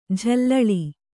♪ jhallaḷi